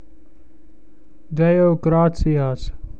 Day-o    graht-see-ass.